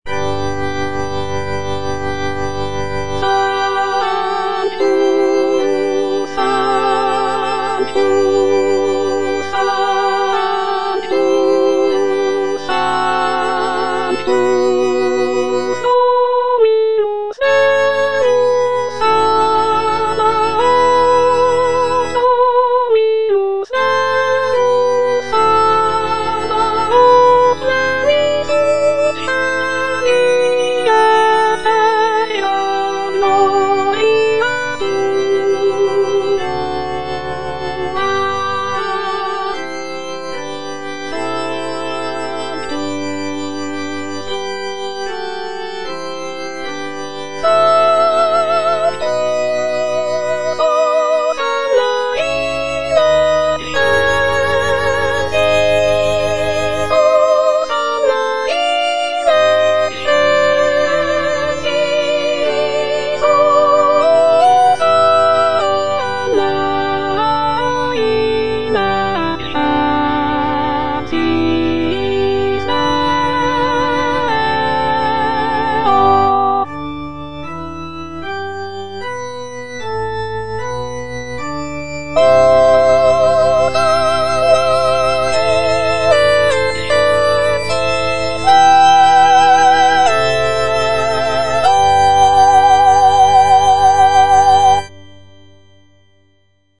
G. FAURÉ, A. MESSAGER - MESSE DES PÊCHEURS DE VILLERVILLE Sanctus (soprano II) (Emphasised voice and other voices) Ads stop: auto-stop
The composition is a short and simple mass setting, featuring delicate melodies and lush harmonies.